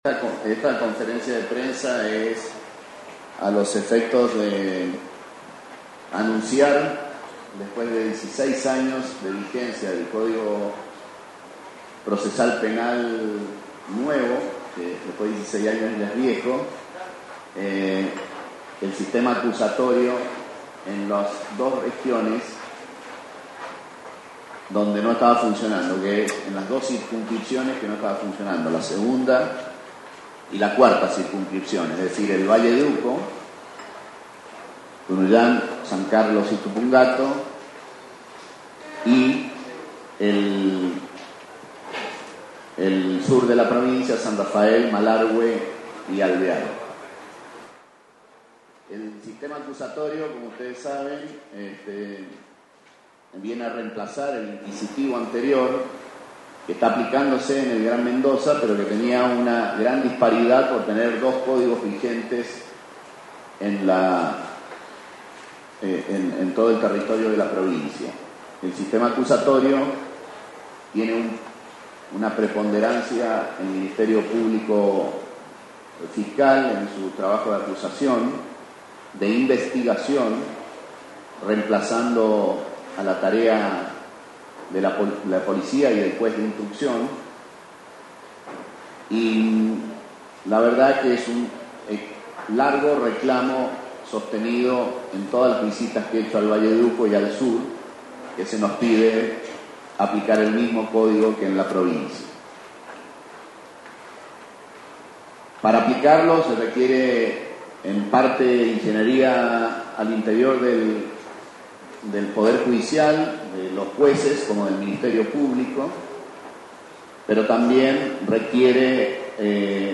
Este mediodía, el Gobernador Alfredo Cornejo, junto al ministro de Gobierno, Justicia y Trabajo, Dalmiro Garay, y al ministro de Seguridad, Gianni Venier, informaron la aplicación del nuevo sistema acusatorio del Código Procesal Penal, Ley 6730, en el Valle de Uco y en la zona Sur.